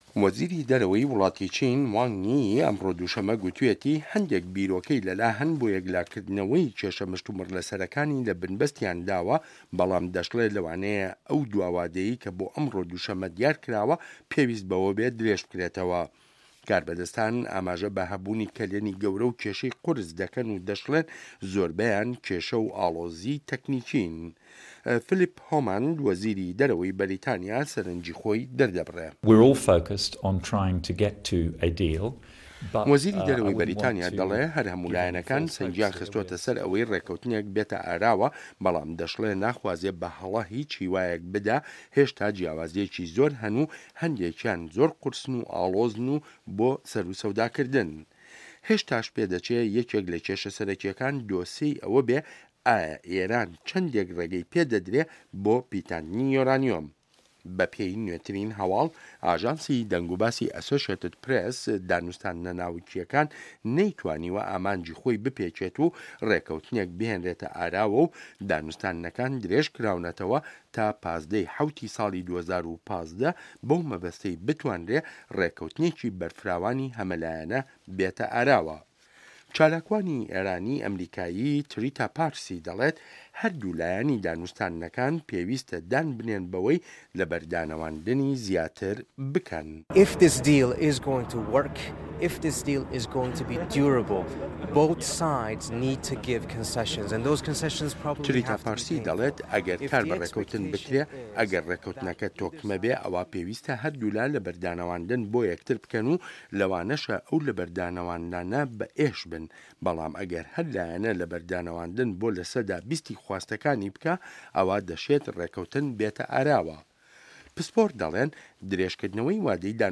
ڕاپـۆرتی ناوکی ئێران